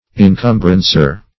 Search Result for " incumbrancer" : The Collaborative International Dictionary of English v.0.48: Incumbrancer \In*cum"bran*cer\, n. (Law) One who holds an incumbrance, or some legal claim, lien, or charge on an estate.